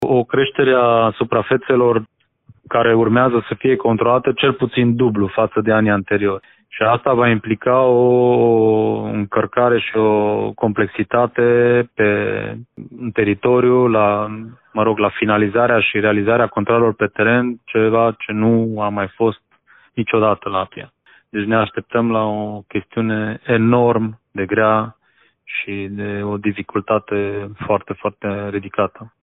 Directorul APIA Mureș, Ovidiu Săvâșcă: